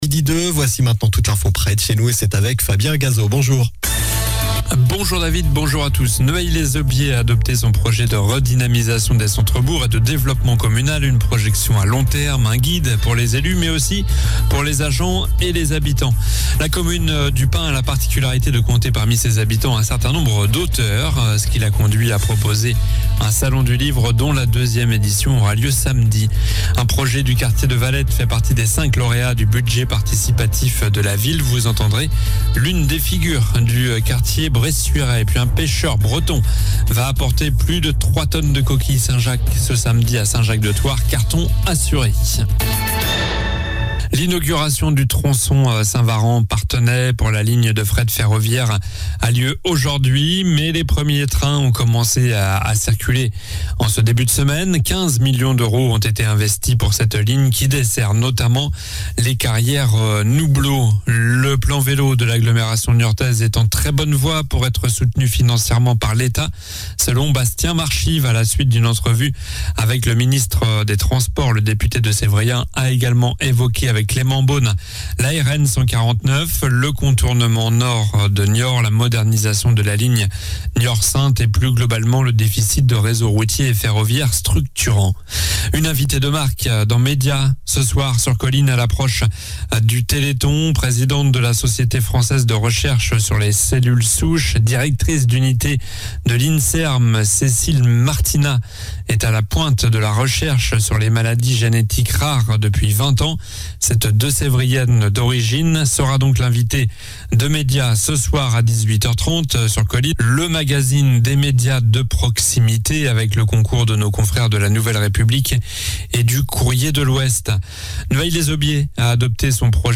Journal du jeudi 07 décembre (midi)